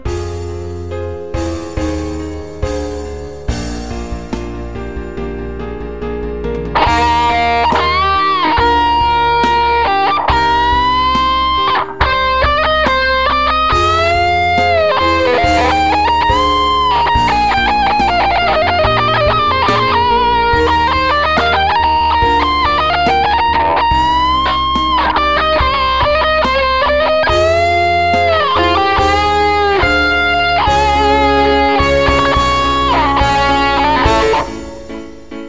old guitar